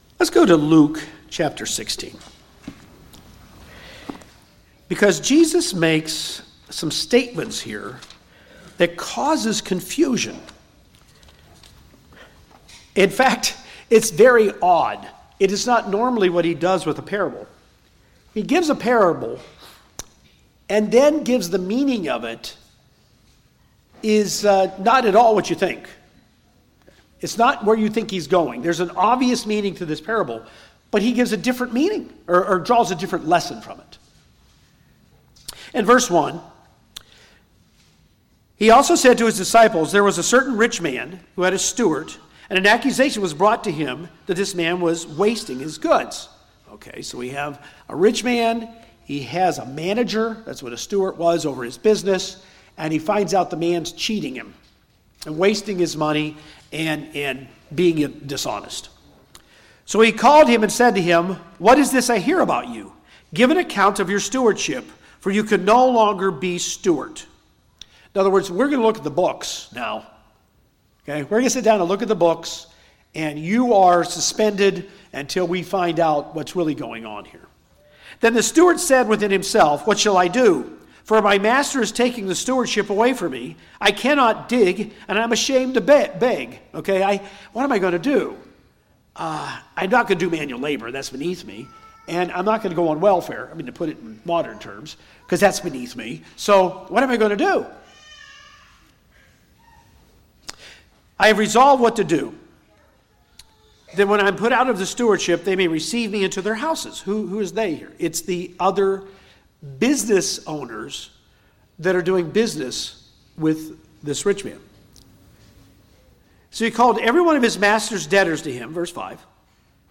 This sermon explores Jesus’ parable of the unjust steward in Luke 16, highlighting the importance of using worldly resources wisely and faithfully to reflect godly values. Key lessons include trusting God to meet needs, practicing honesty in financial dealings, showing generosity to others, cultivating a strong work ethic, and finding contentment in any situation.